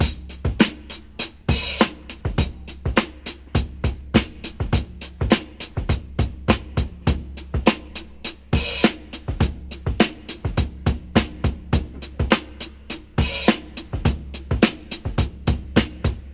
103 Bpm Drum Loop C# Key.wav
Free drum loop sample - kick tuned to the C# note.
.WAV .MP3 .OGG 0:00 / 0:16 Type Wav Duration 0:16 Size 2,75 MB Samplerate 44100 Hz Bitdepth 16 Channels Stereo Free drum loop sample - kick tuned to the C# note.
103-bpm-drum-loop-c-sharp-key-ZI0.ogg